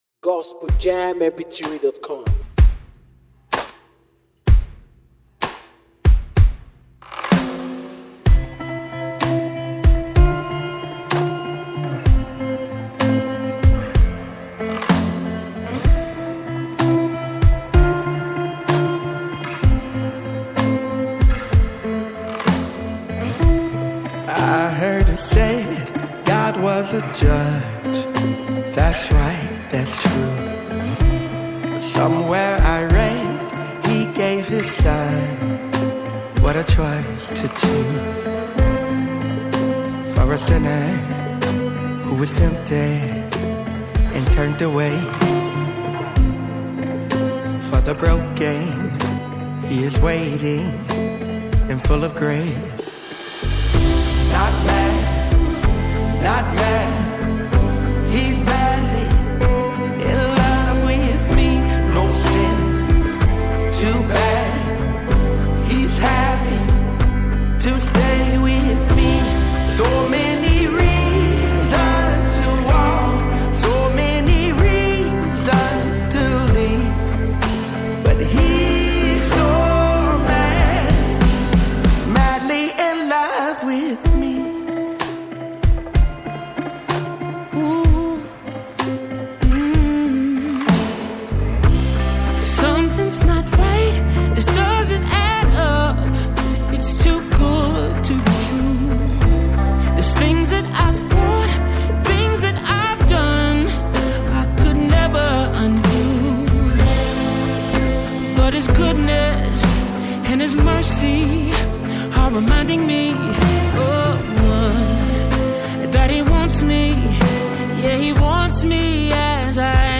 A contemporary gospel song with a soulful and rhythmic feel.